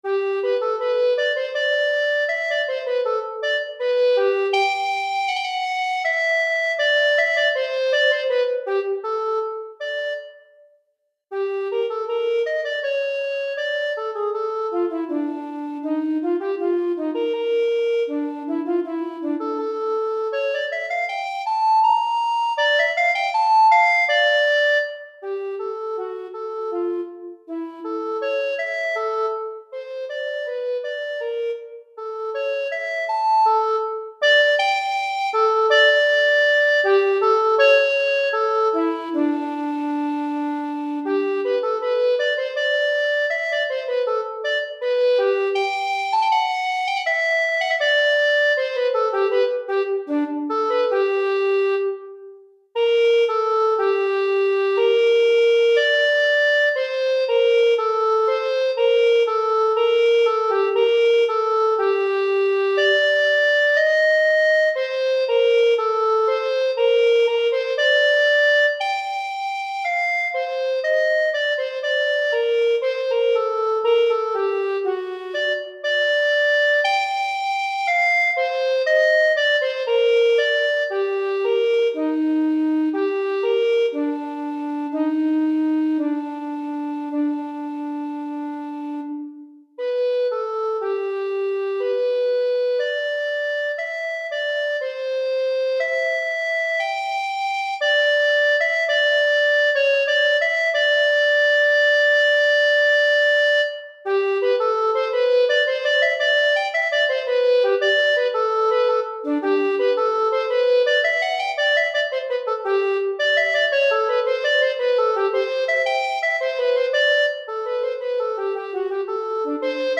Pour flûte à bec solo DEGRE FIN de CYCLE 1